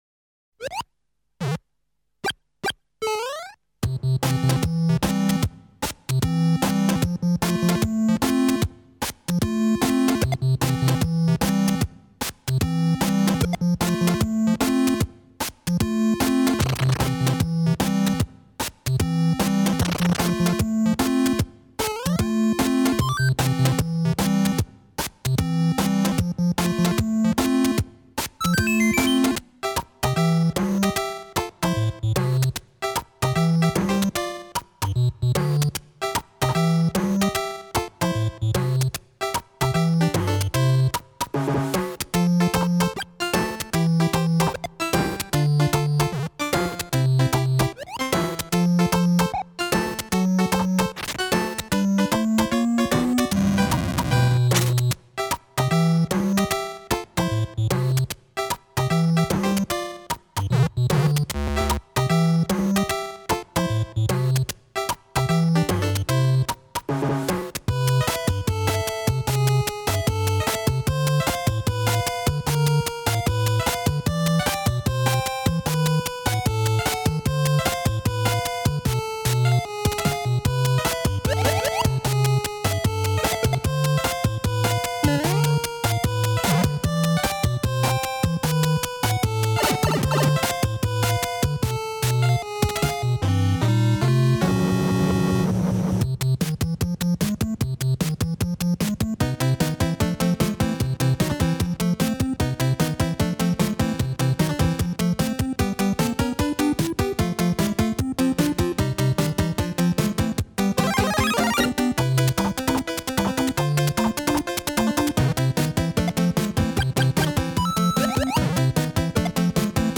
原声音乐